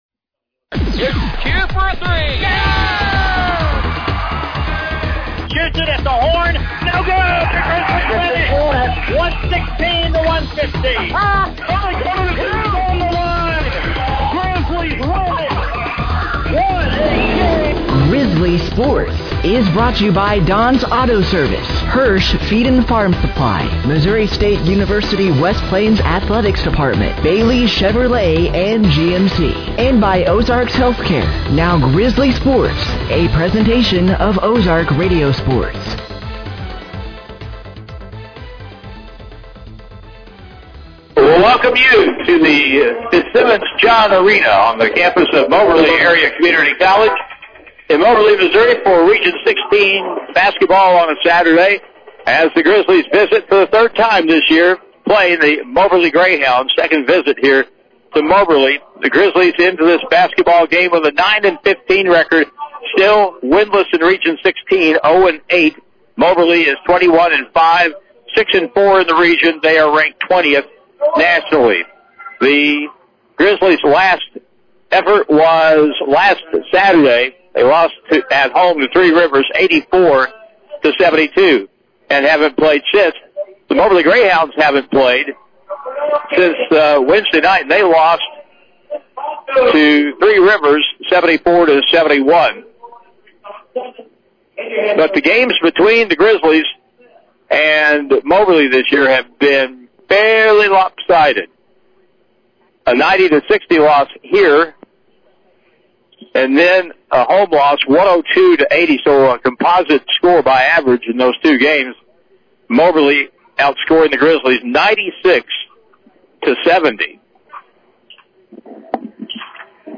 The Missouri State West Plains Grizzly Basketball Team made a return to the Fitzsimmons John Arena on The Campus of Moberly Area Community College in Moberly, Missouri on Saturday, February 14th, 2026.